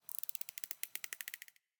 Minecraft Version Minecraft Version 1.21.5 Latest Release | Latest Snapshot 1.21.5 / assets / minecraft / sounds / mob / dolphin / idle_water2.ogg Compare With Compare With Latest Release | Latest Snapshot
idle_water2.ogg